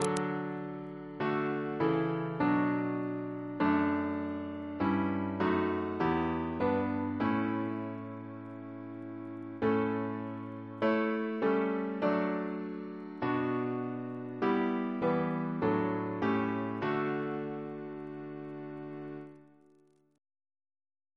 Double chant in D Composer: Richard Massey (1798-1883) Reference psalters: ACP: 261; PP/SNCB: 223